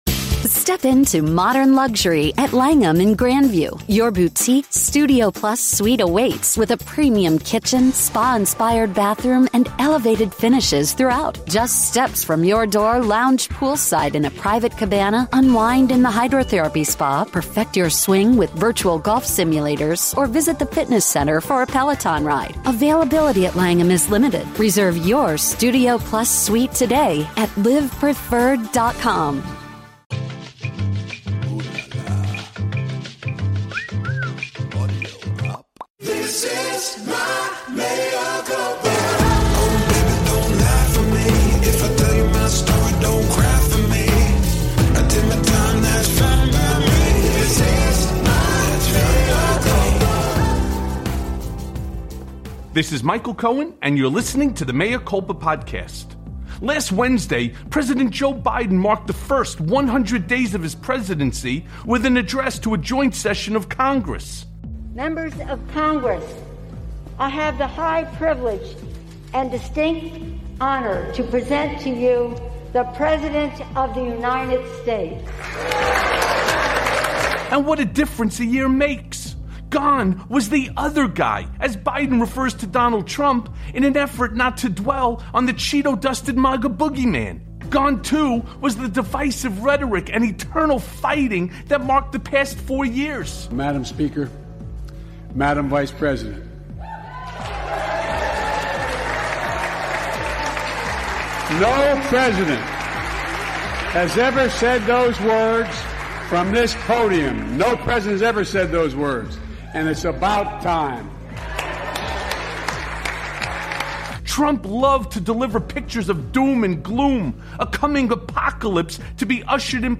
Michael looks at how the changing political calculus made this transformation possible. Hasan Piker talks progressive politics and social media in a Mea Culpa Conversation for the ages.